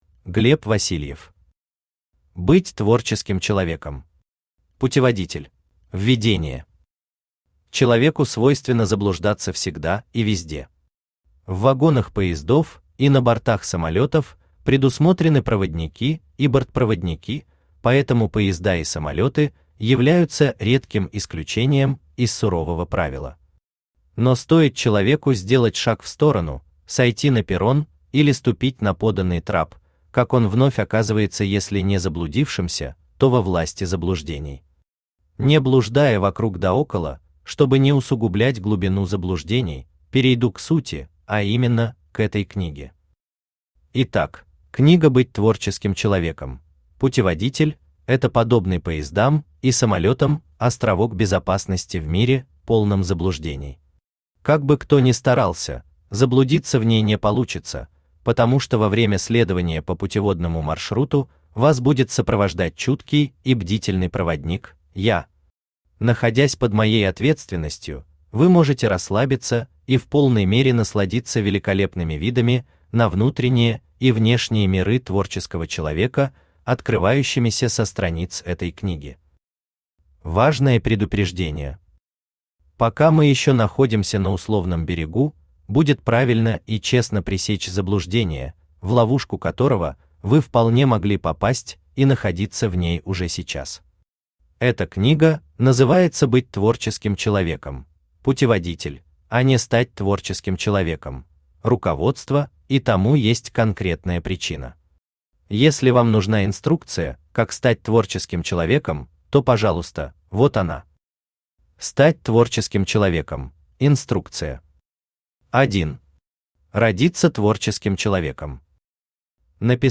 Аудиокнига Быть творческим человеком. Путеводитель | Библиотека аудиокниг